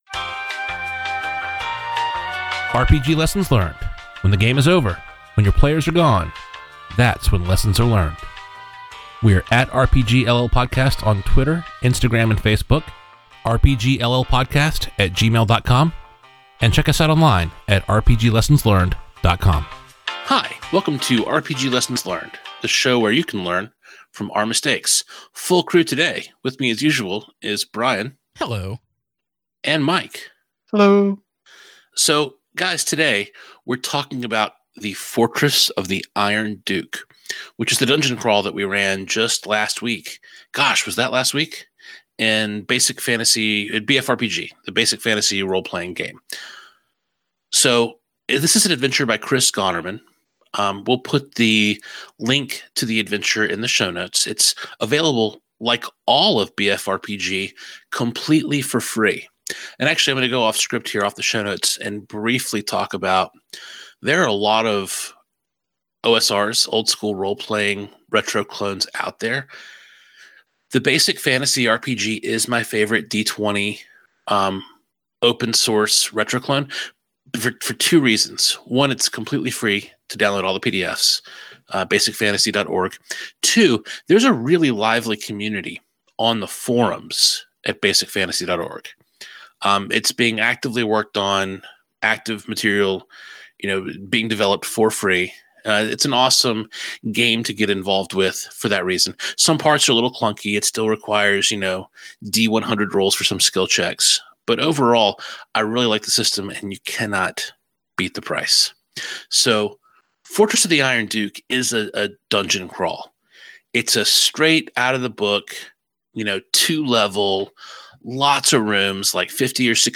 The guys gather around the table for their first dungeon crawl in a long time.